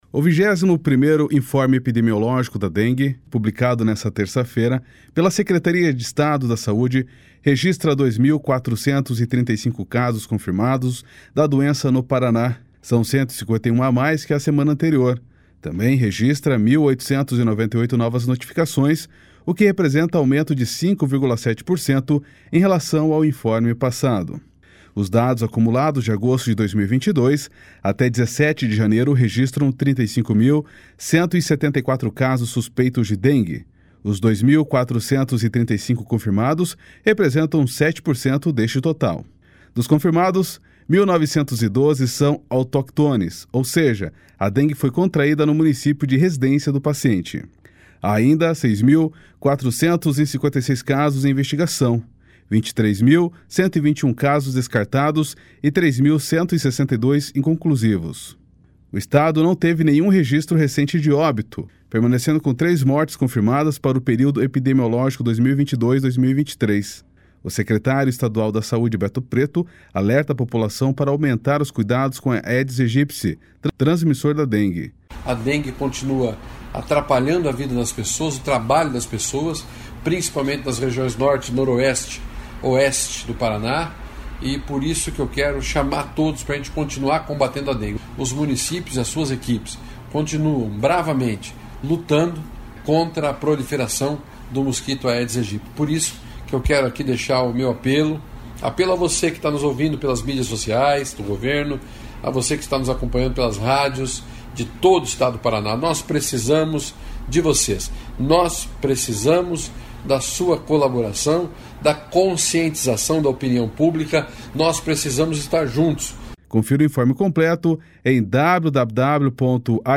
O Estado não teve nenhum registro recente de óbito, permanecendo com três mortes confirmadas para o período epidemiológico 2022-2023. O secretário estadual da Saúde, Beto Preto, alerta a população para aumentar os cuidados com o Aedes Aegypti, transmissor da dengue.